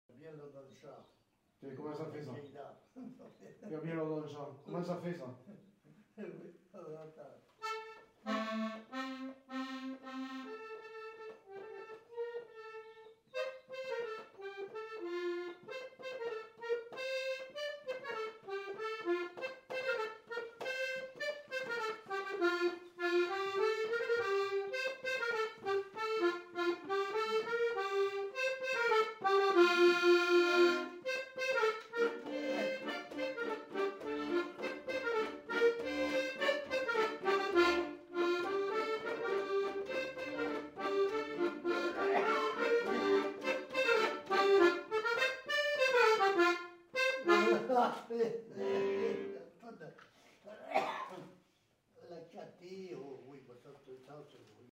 Aire culturelle : Quercy
Lieu : Chasteaux
Genre : morceau instrumental
Instrument de musique : accordéon chromatique
Danse : bourrée